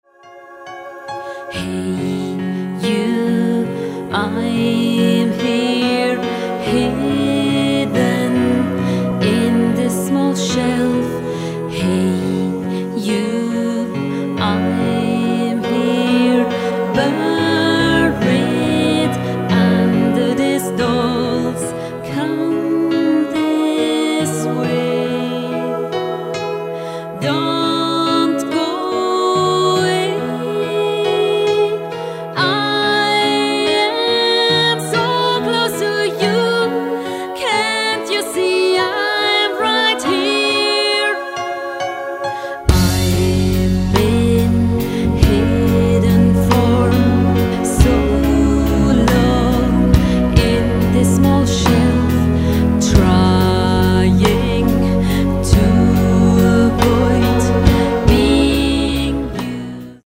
(low quality)